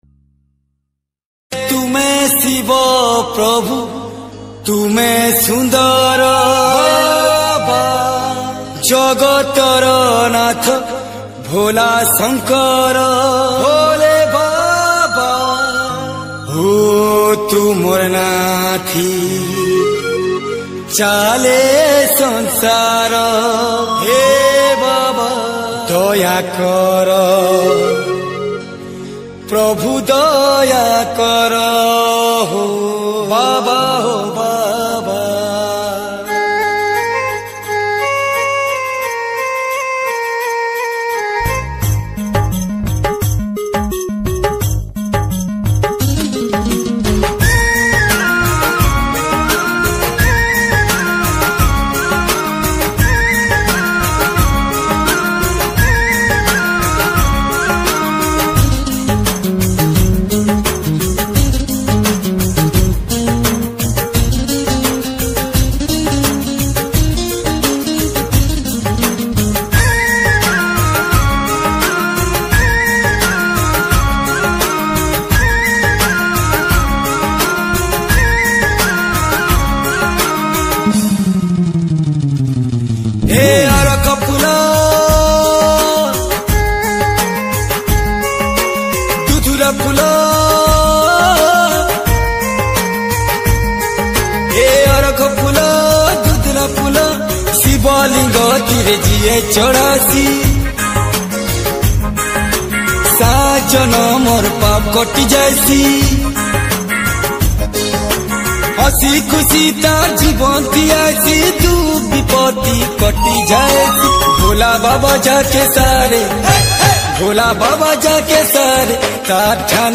Bolbum Special Song